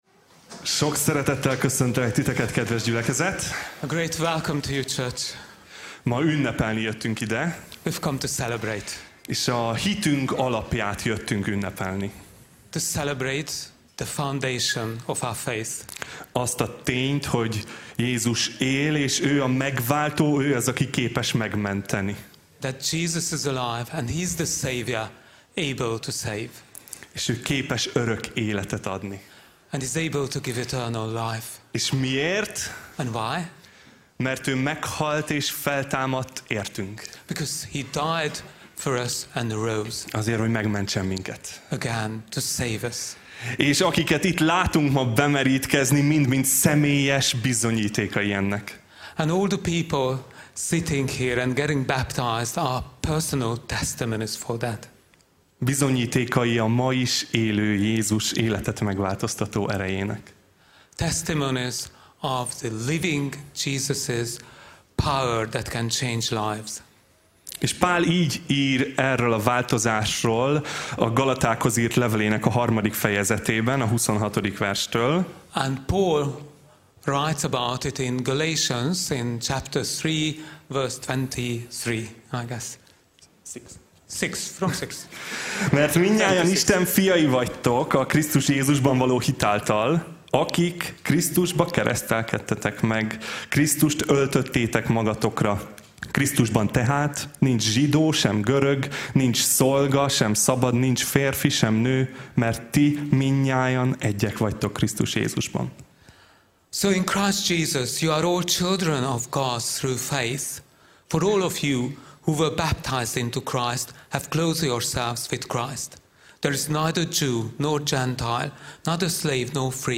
Az új élet | Bemerítés & dicsőítő este